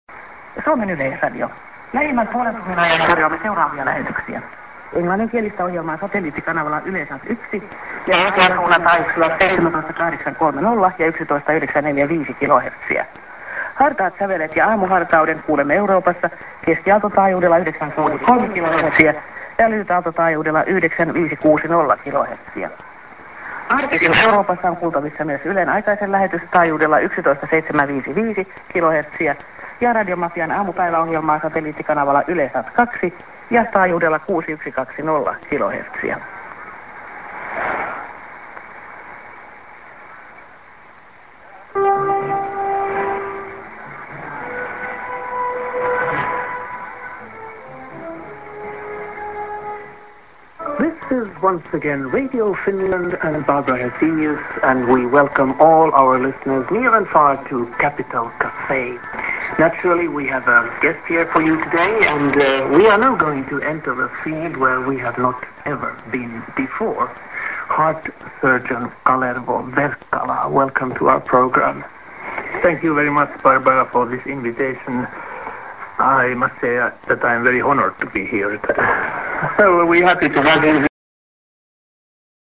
End ->SKJ(women)->55":ID(man)